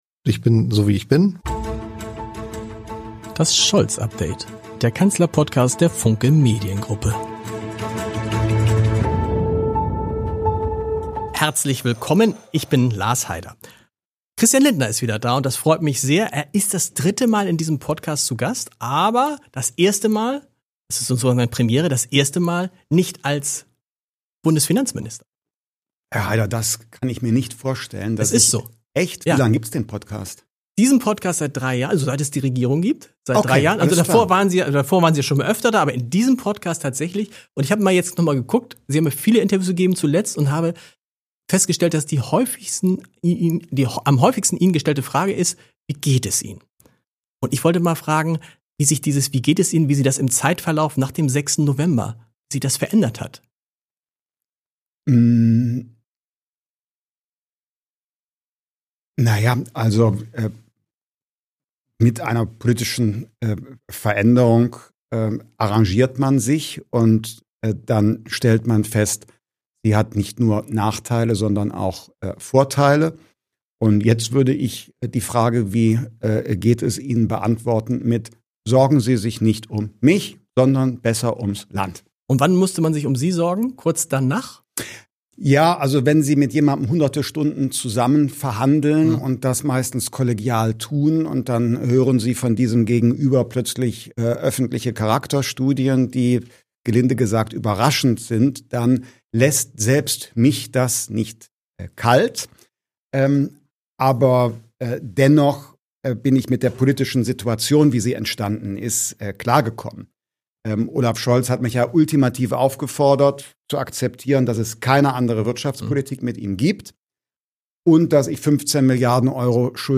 Er ist bereits das dritte Mal in diesem Podcast zu Gast – und trotzdem ist dieser Auftritt eine Premiere: Denn erstmalig kommt Christian Lindner nicht als Bundesfinanzminister, sondern „nur“ als FDP-Vorsitzender und Spitzenkandidat seiner Partei für die Bundestagswahl Ende Februar. Lindner erzählt, wie sein Auszug aus dem Bundesfinanzministerium gelaufen ist, er spricht über den Sinn und Unsinn von Rücktritten, über das eigentliche Wahlziel der FDP, über den komplett auf ihn zugeschnitten Wahlkampf und sein Verhältnis zu Friedrich Merz.